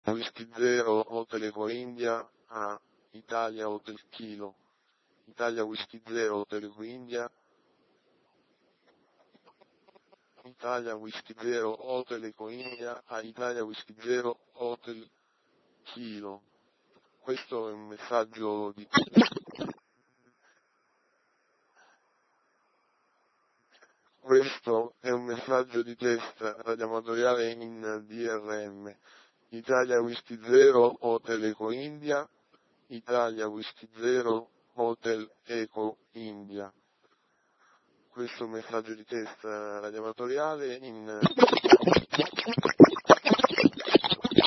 (Drm Mode b - BandWidth 2,25 khz Codfm 16 QAM)